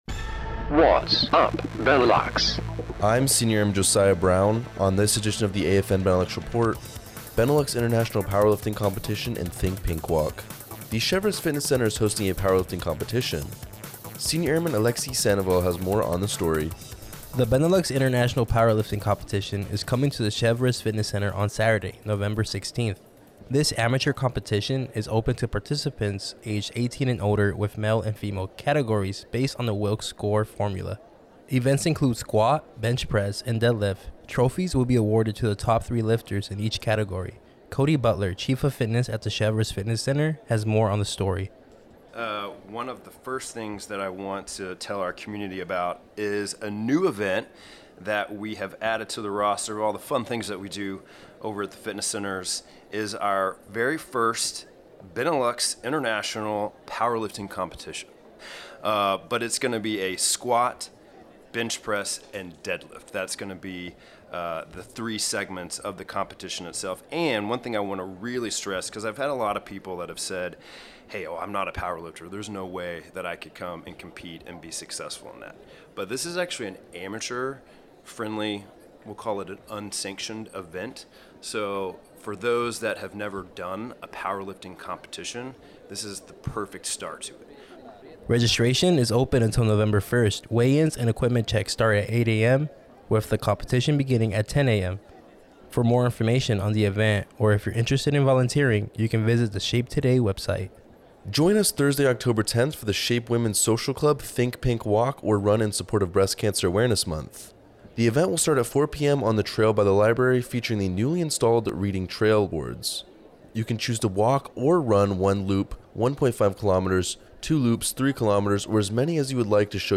American Forces Network Benelux reports on current and future events, the Supreme Headquarters Allied Powers Europe Safety Improvements and Strategic Warfighting Seminar, Oct. 8, 2024, for a radio broadcast out of SHAPE, Belgium.